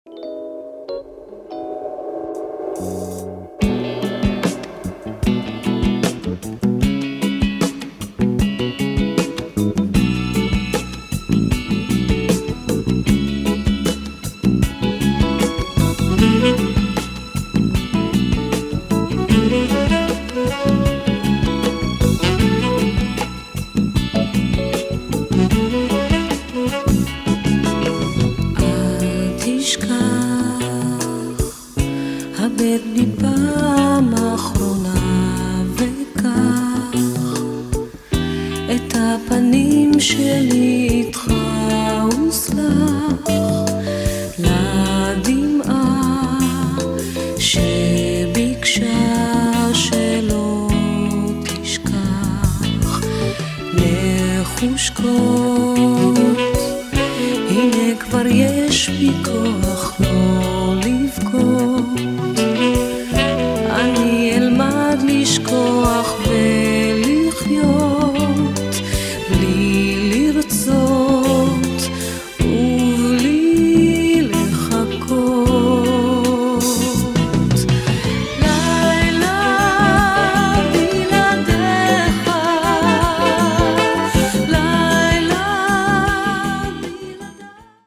sweet soul groove
Then the electric piano enters…exquisite !